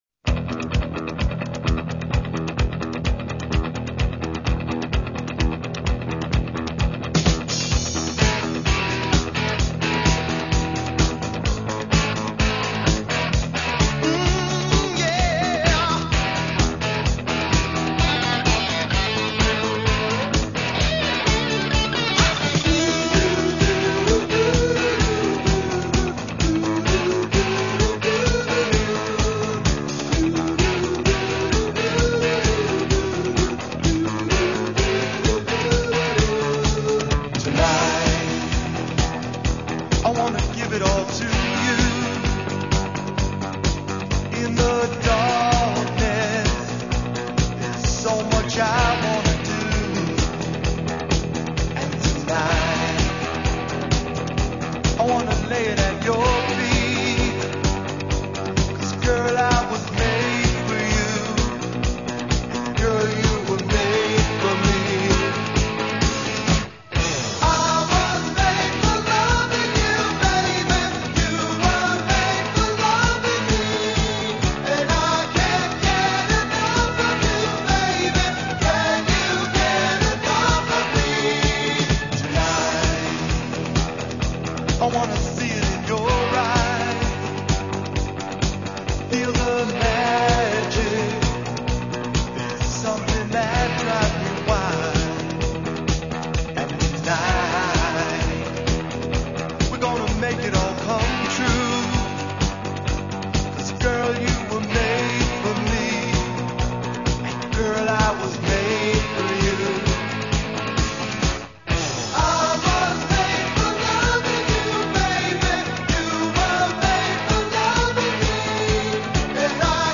22kHz Mono